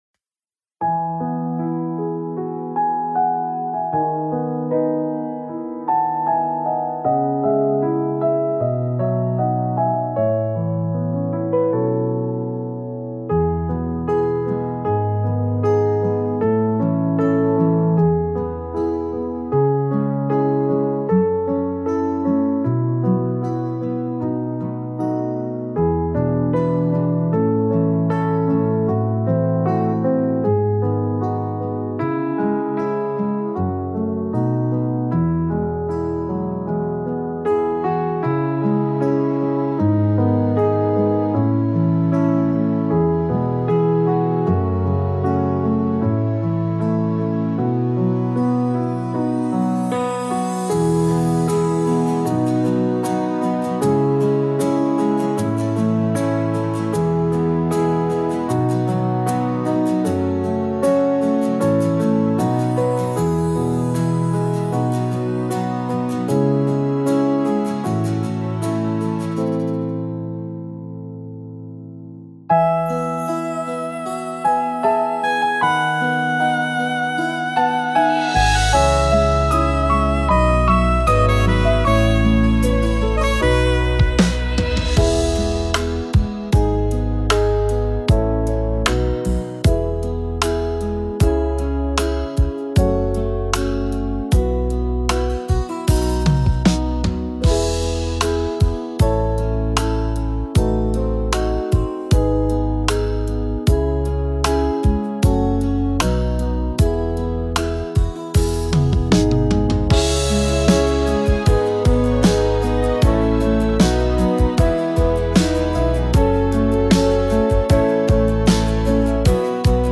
•   Beat  02.